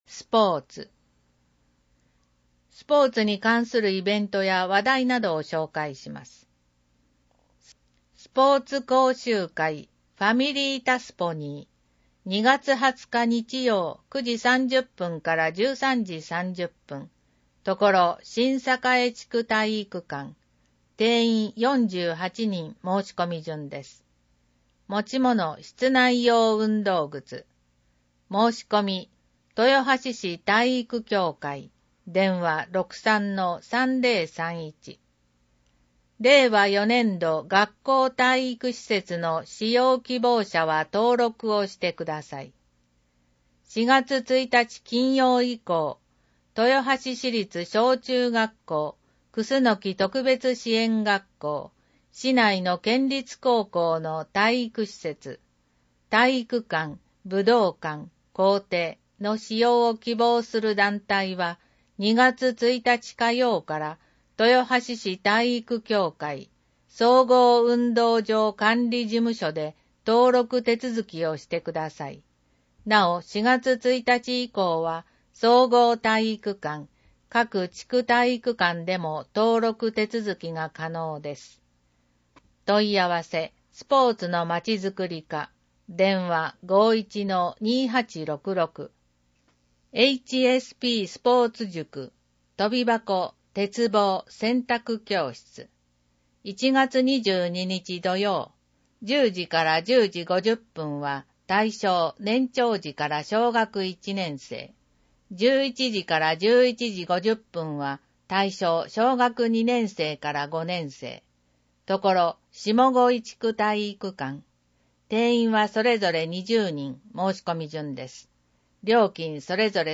• 「広報とよはし」から一部の記事を音声でご案内しています。視覚障害者向けに一部読み替えています。
（音声ファイルは『音訳グループぴっち』提供）